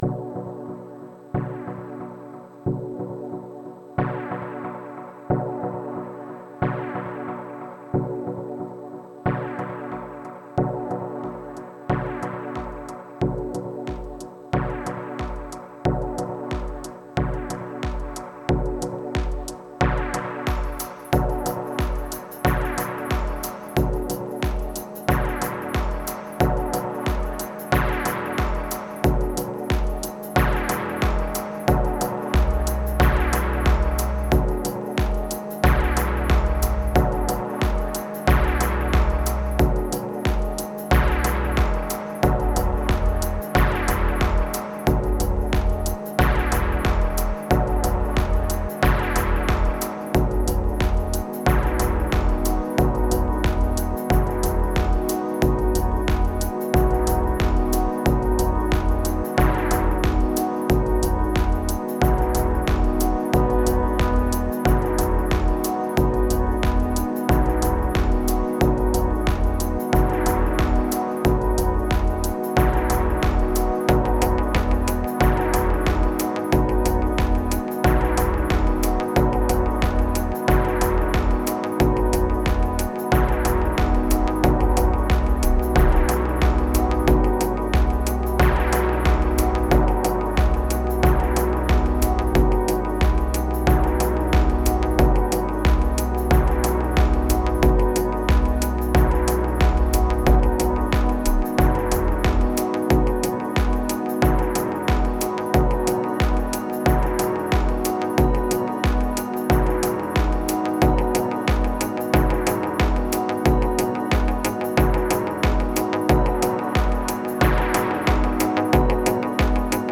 (MPC X + Xone 92 + Eventide Space)
623📈 - 80%🤔 - 91BPM🔊 - 2021-04-24📅 - 246🌟
Kicks Energy Resume Deep Dark Shore Waves Memories Midnight